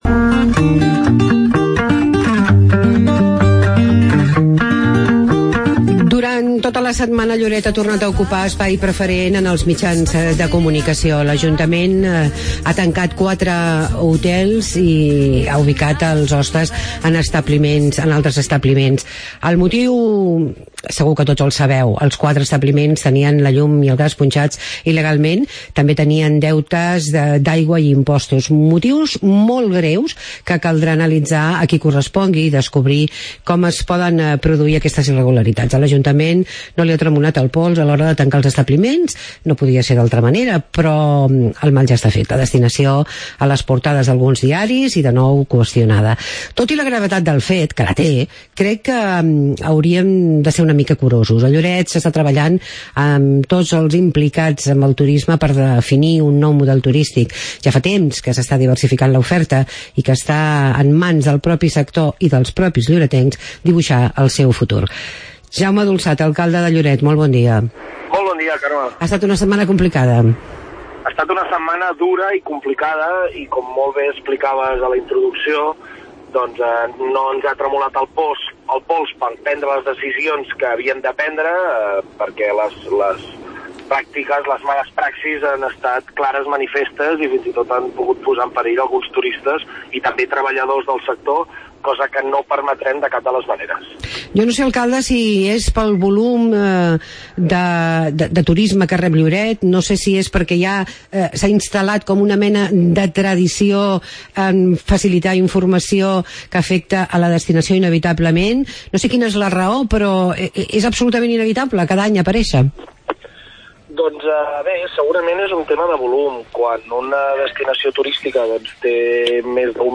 Entrevista amb Jaume Dulsat, alcalde de Lloret de Mar